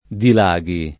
[ dil #g i ]